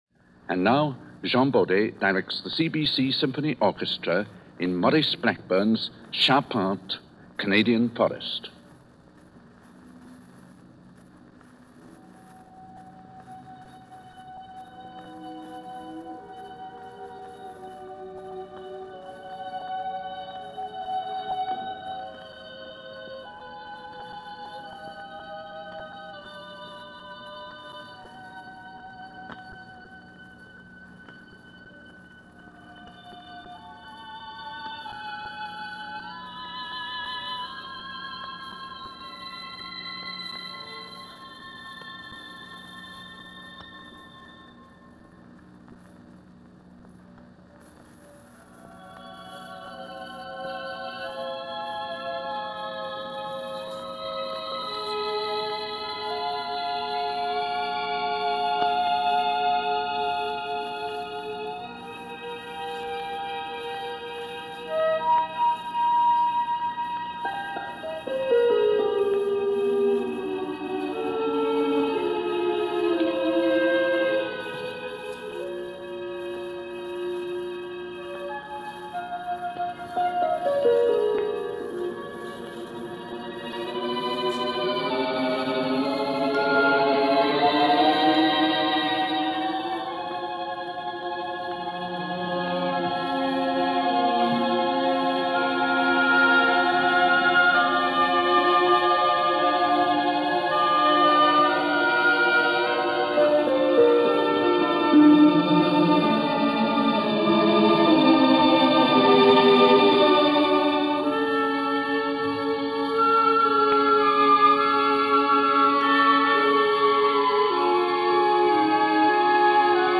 premier performance